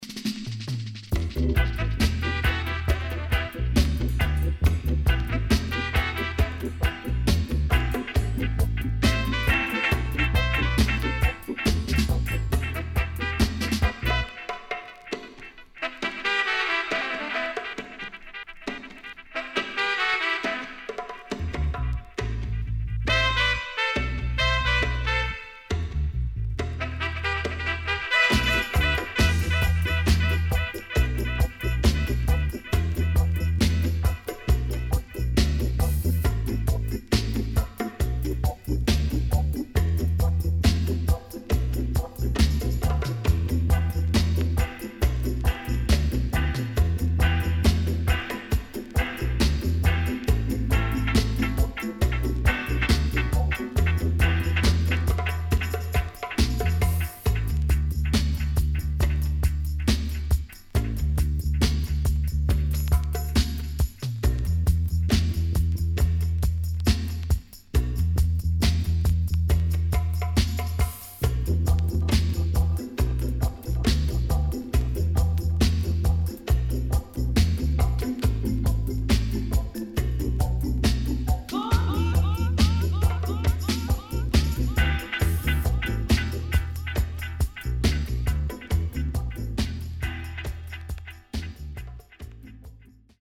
SIDE A:所々チリノイズがあり、少しプチパチノイズ入ります。